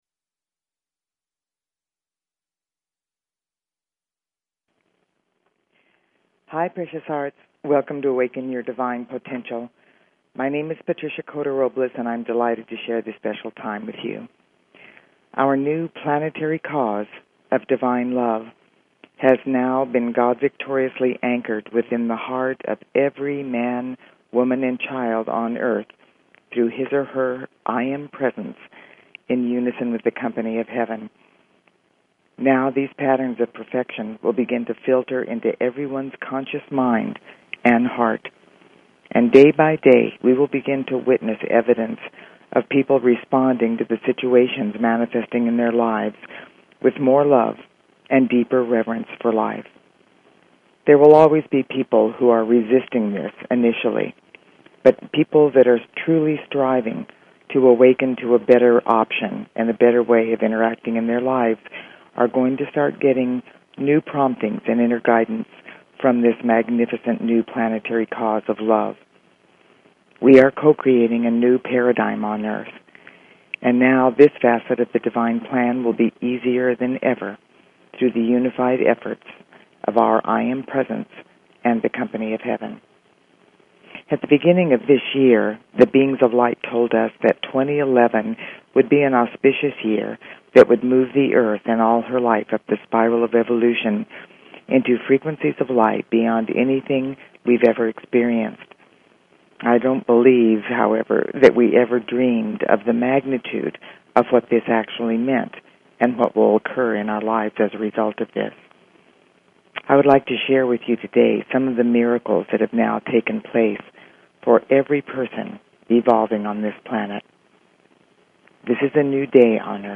Talk Show Episode, Audio Podcast, Awaken_Your_Divine_Potential and Courtesy of BBS Radio on , show guests , about , categorized as